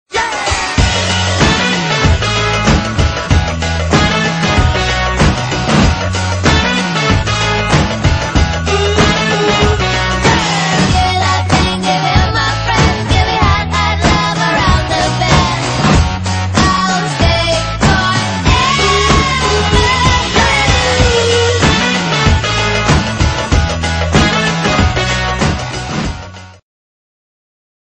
un nouveau générique :